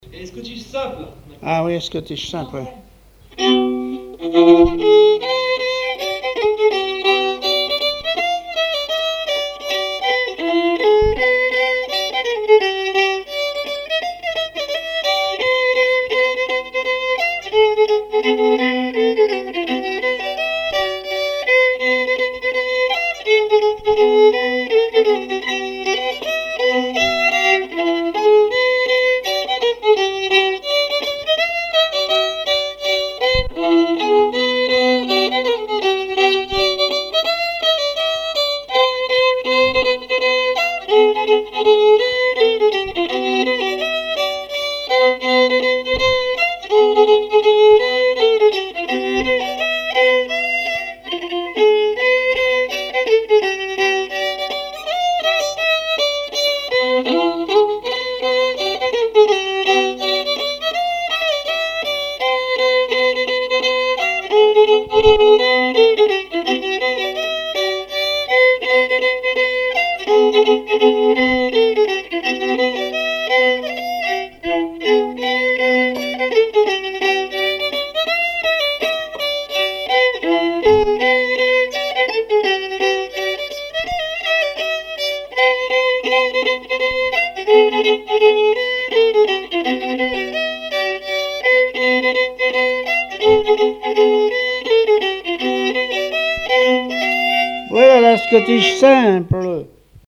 scottich trois pas
répertoire musical au violon
Pièce musicale inédite